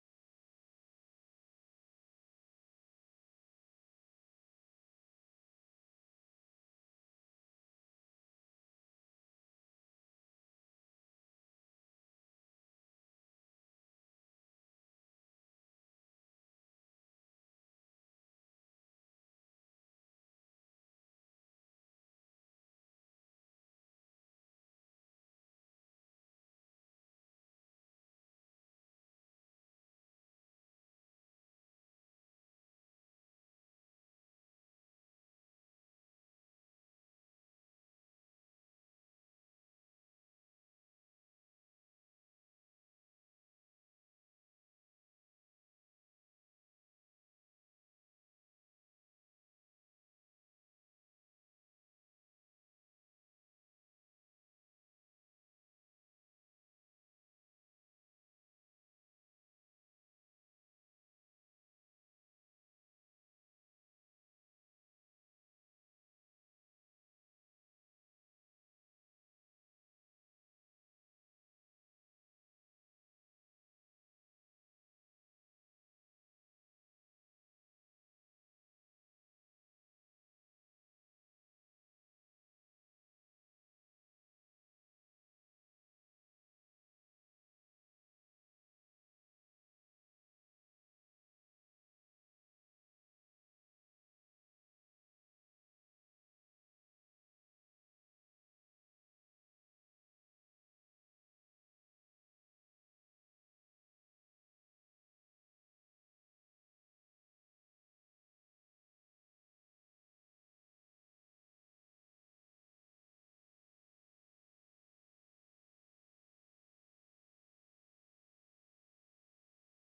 Praise & Worship at FWC on June 15 2025